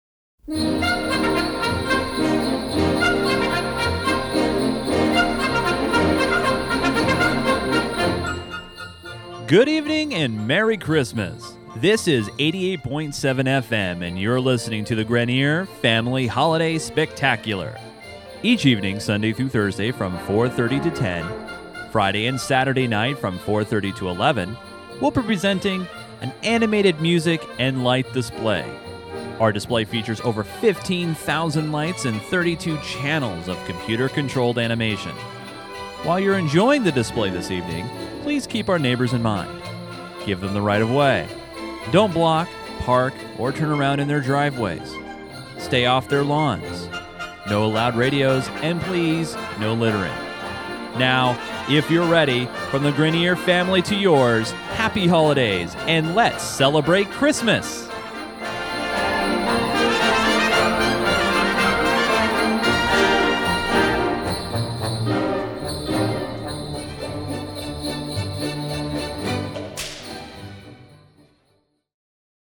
A voiceover for the holidays!